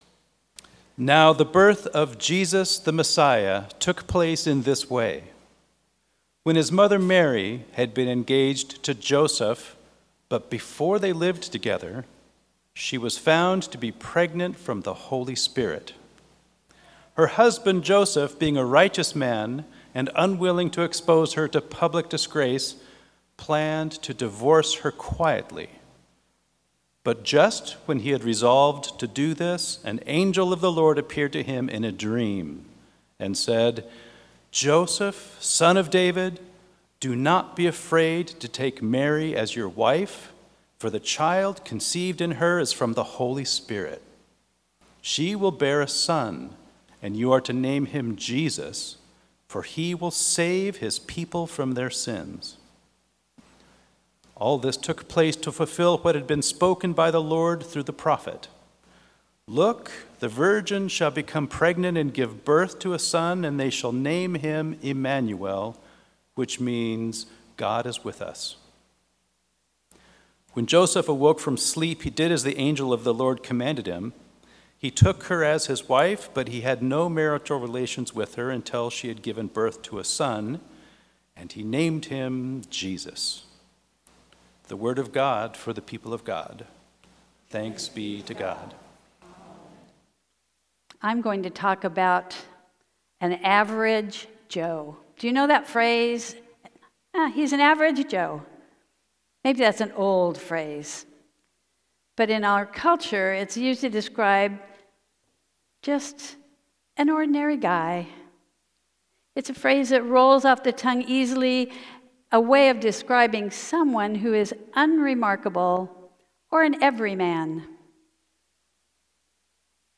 Fourth Sunday of Advent sermon